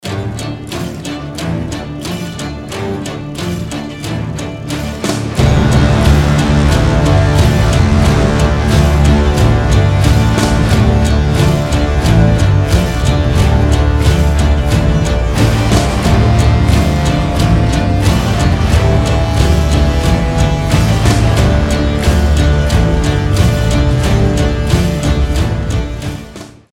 • Качество: 320, Stereo
крутые
без слов
инструментальные